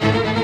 strTTE65024string-A.wav